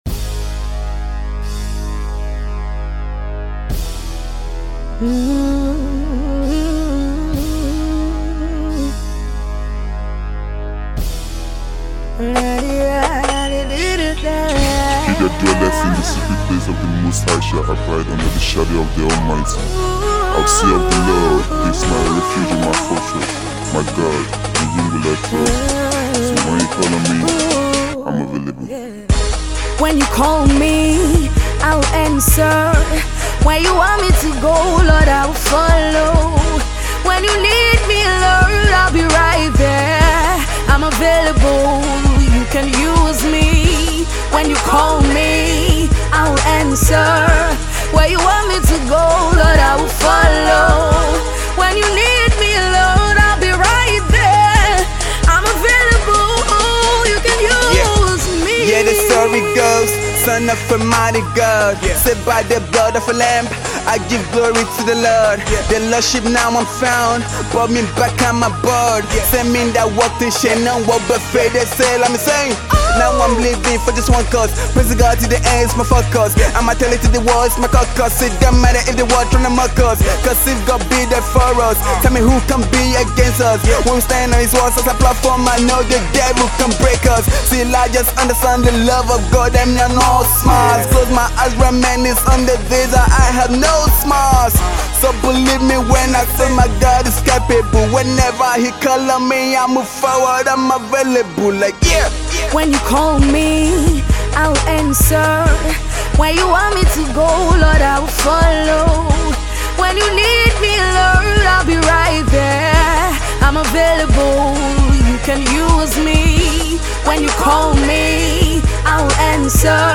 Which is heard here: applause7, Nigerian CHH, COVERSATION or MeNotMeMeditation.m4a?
Nigerian CHH